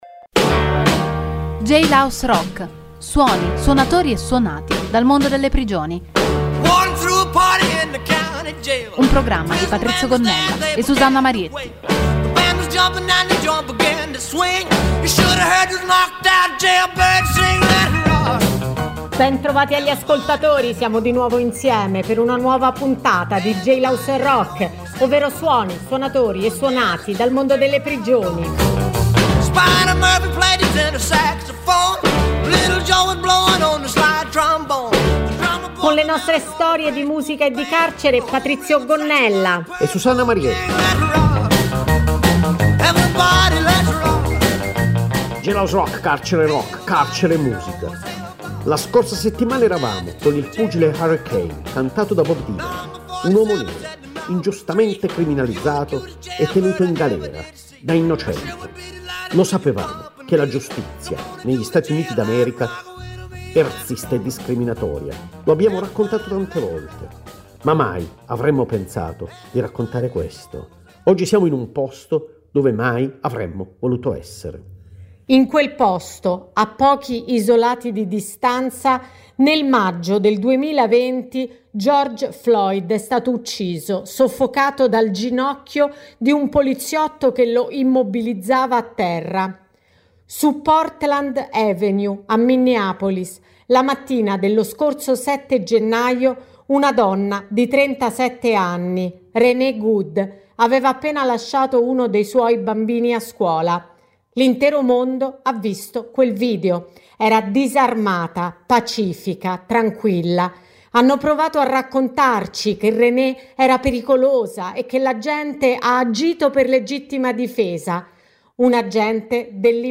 il programma include storie e suoni dal mondo delle prigioni, con la partecipazione di detenuti dei carceri di Rebibbia e Bollate che realizzano un Giornale Radio dal Carcere e cover di artisti.